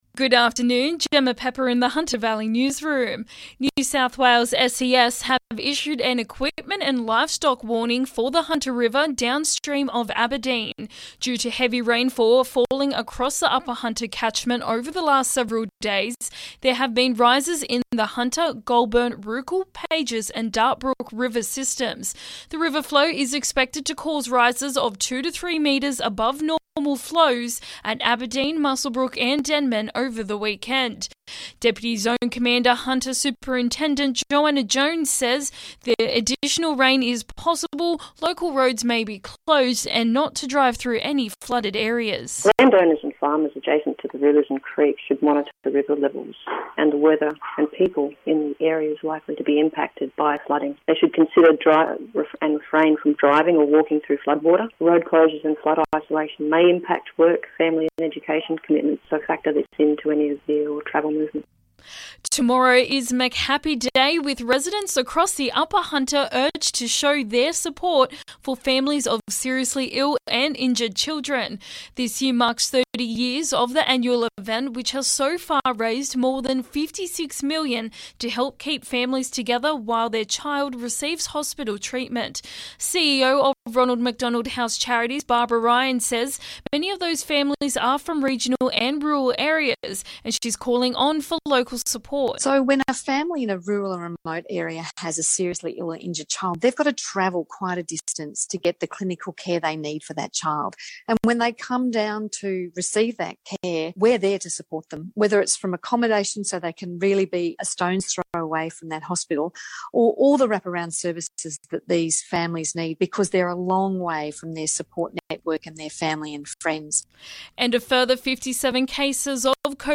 LISTEN: Hunter Valley Local News Headlines 12/11/2021